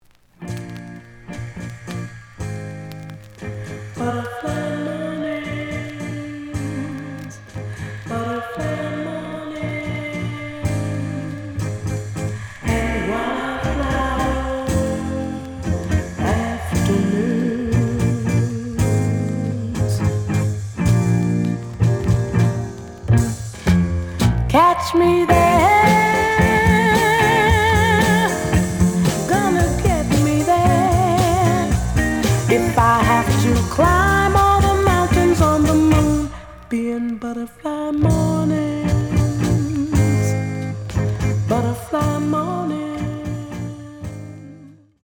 The audio sample is recorded from the actual item.
●Genre: Soul, 70's Soul
Slight edge warp.